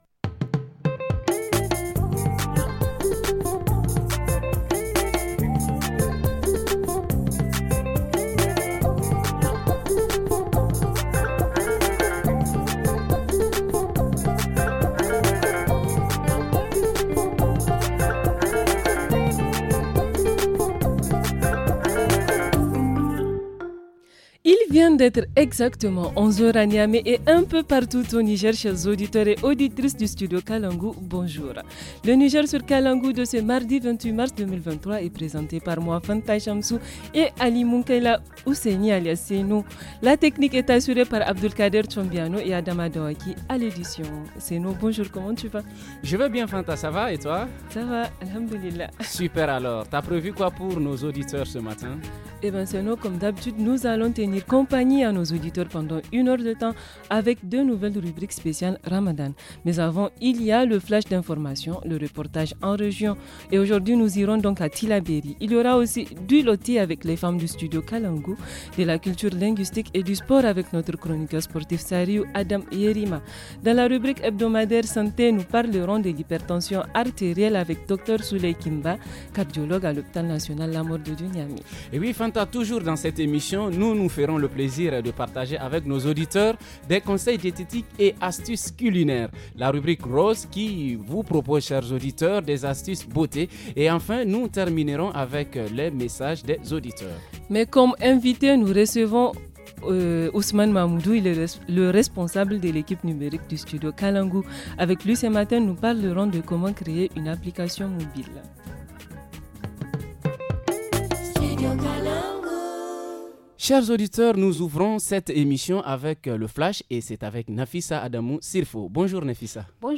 – Entretien :